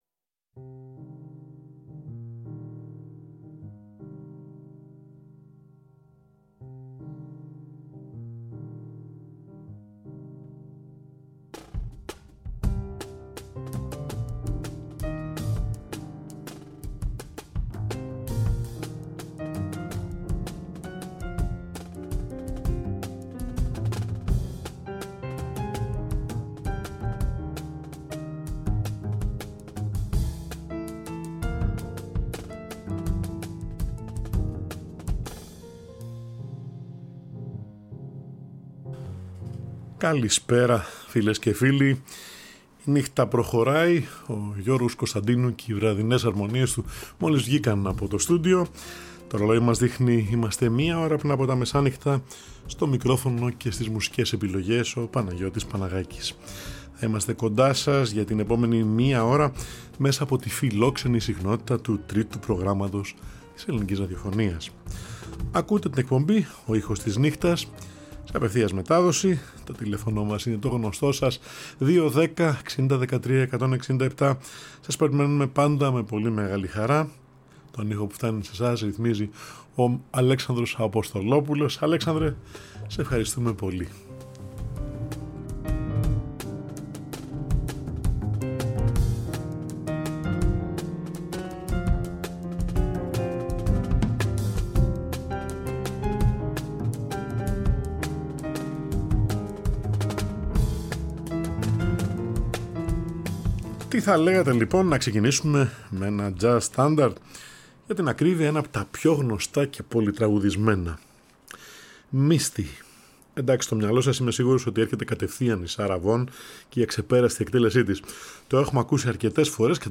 Κάθε Τρίτη και Πέμπτη στις έντεκα, ζωντανά στο Τρίτο Πρόγραμμα